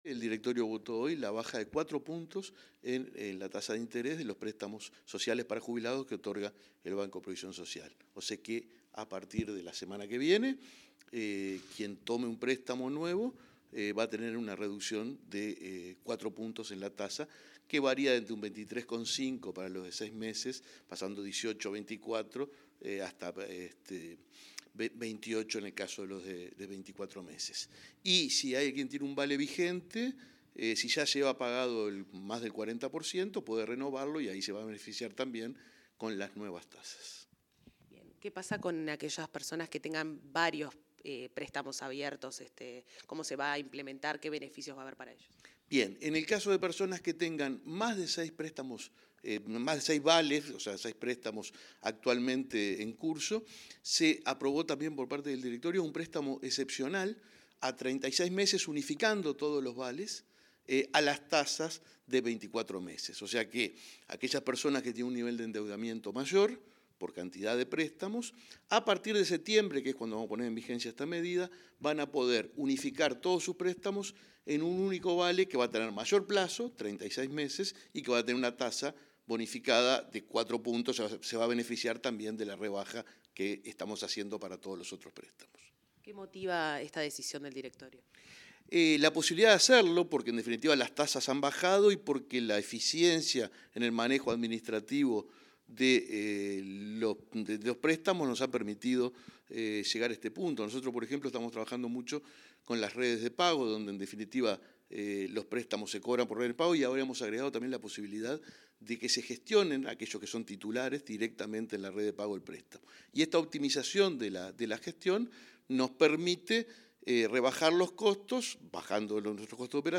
Entrevista al presidente el BPS, Alfredo Cabrera